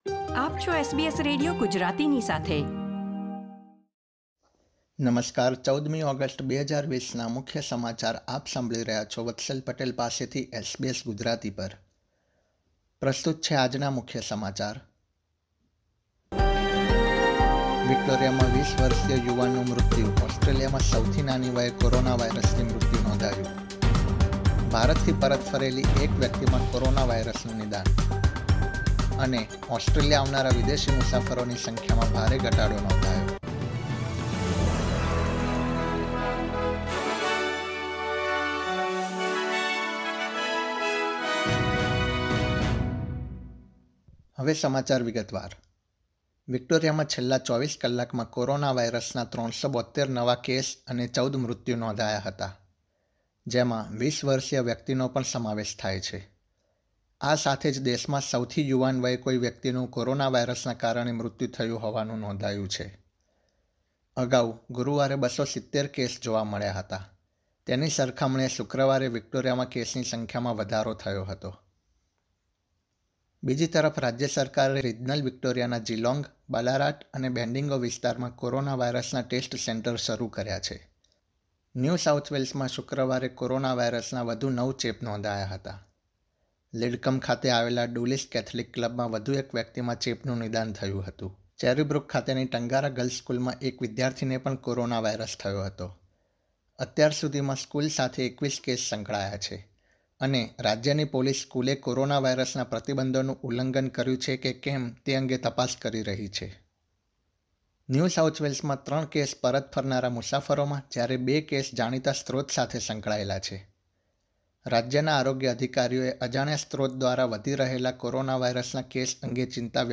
SBS Gujarati News Bulletin 14 August 2020
gujarati_1408_newsbulletin.mp3